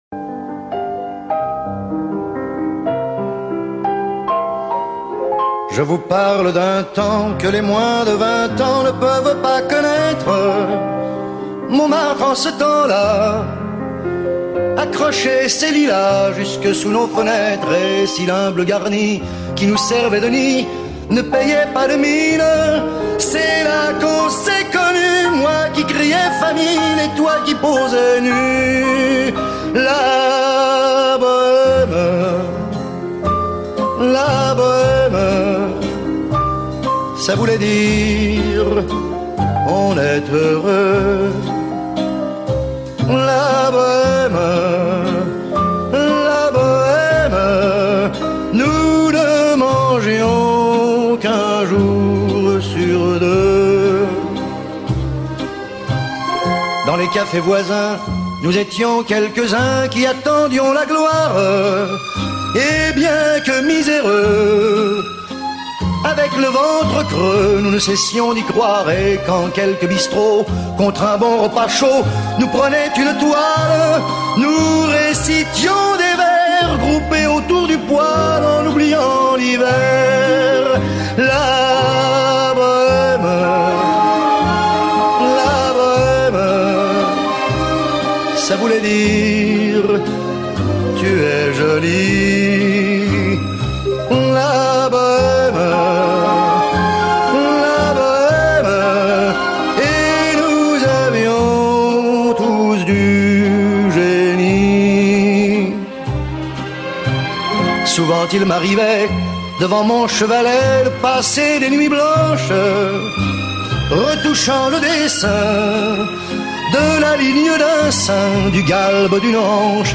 Ultimul gigant al şansonetei